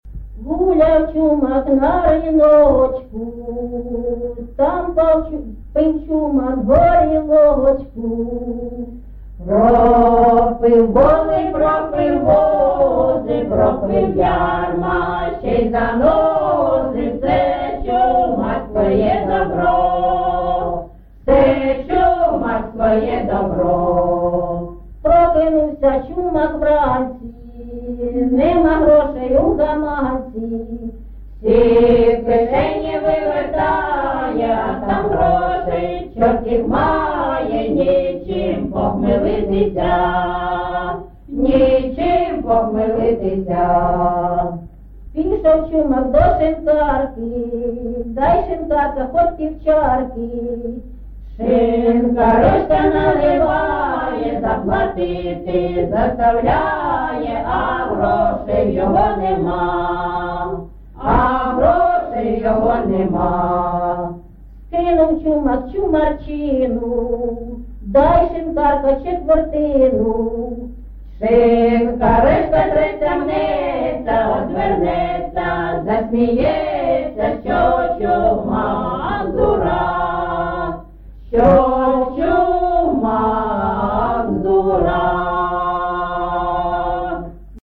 ЖанрЧумацькі
Місце записум. Єнакієве, Горлівський район, Донецька обл., Україна, Слобожанщина